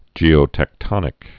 (jēō-tĕk-tŏnĭk)